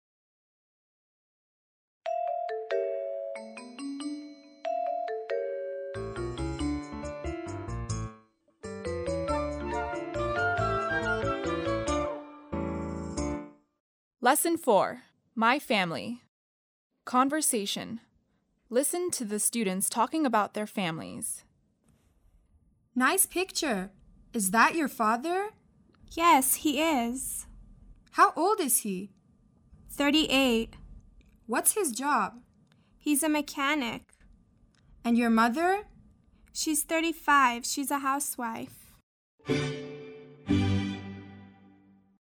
7-Lesson4-Conversation.mp3